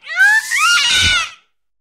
Cri de Fulgulairo dans Pokémon HOME.